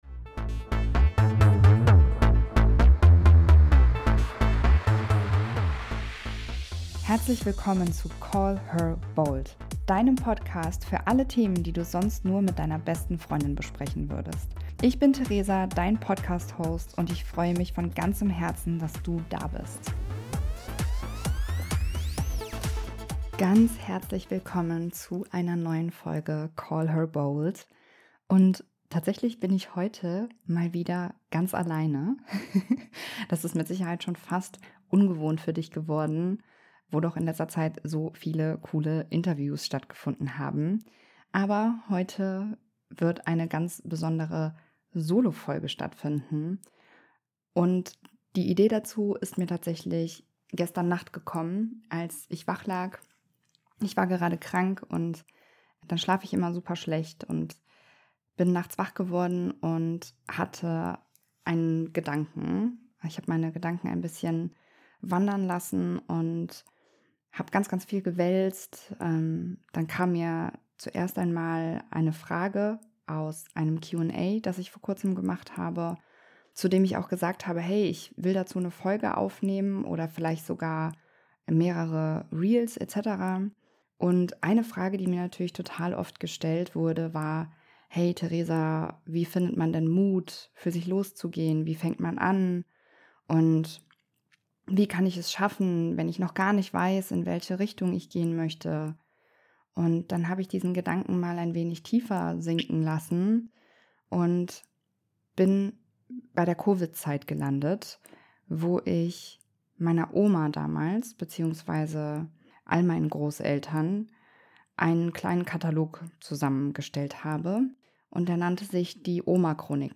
In dieser Solo-Episode spreche ich offen, ehrlich und unfassbar inspirierend darüber, was es wirklich braucht, um für sich selbst loszugehen.